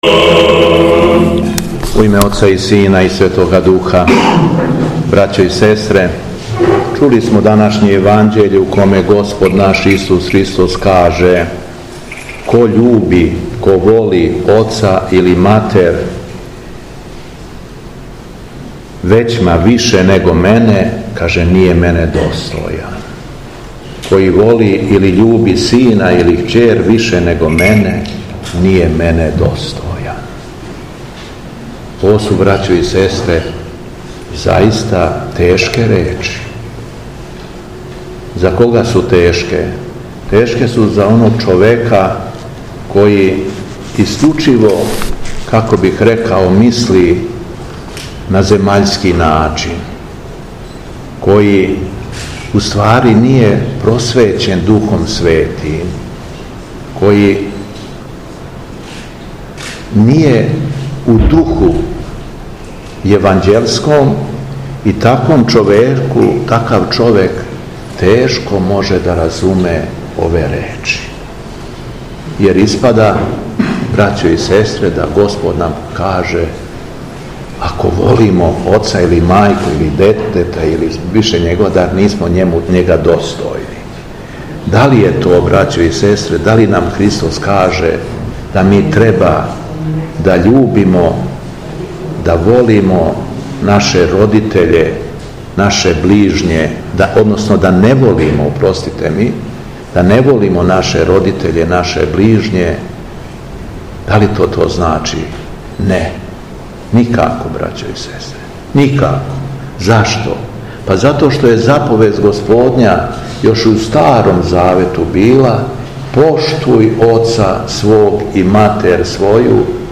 Духовна поука Његовог Високопреосвештенства Митрополита шумадијског г. Јована
Након прочитаног зачала из Светог Јеванђеља Високопреосвећени Митрополит обратио се верном народу речима: